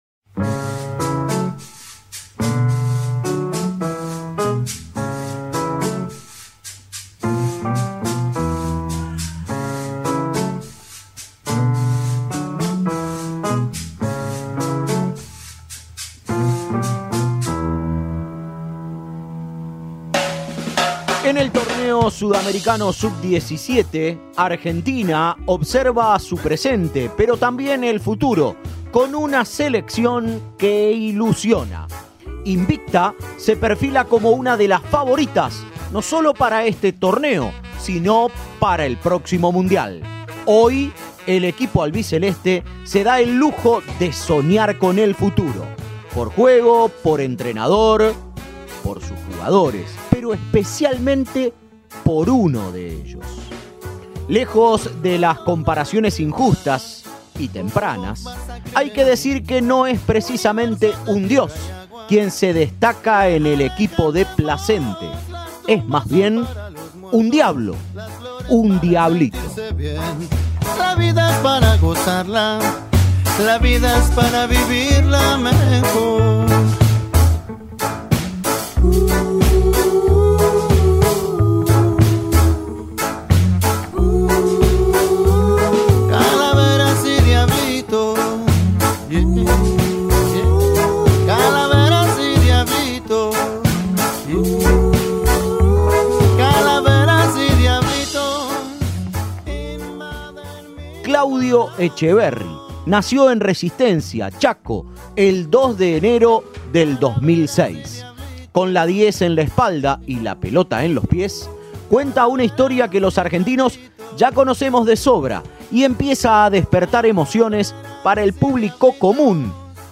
De 17 años, es la gran promesa de la reserva de River Plate y de la selección argentina sub-17. En este informe especial de Cadena 3, repasamos su historia y su presente.